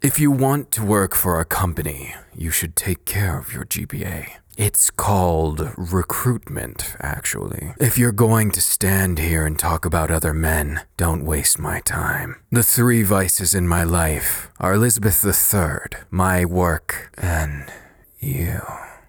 He should basically have a deeper and more refined voice.